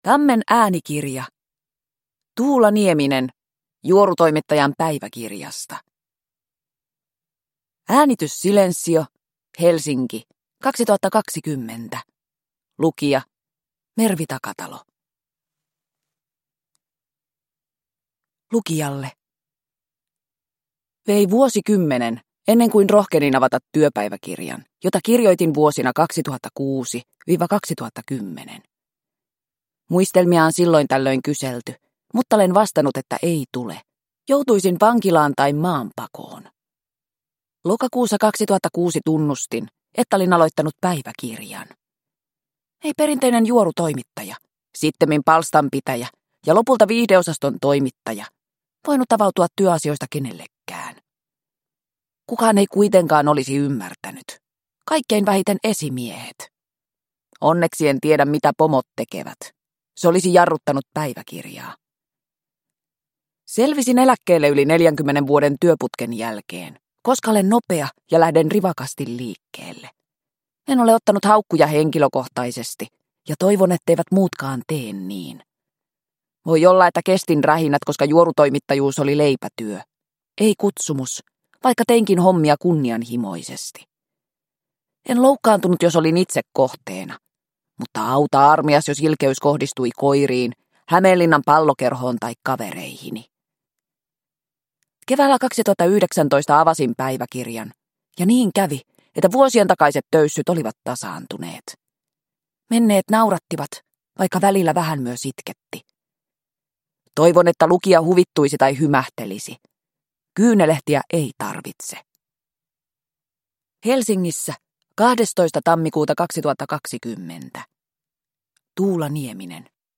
Juorutoimittajan päiväkirjasta – Ljudbok – Laddas ner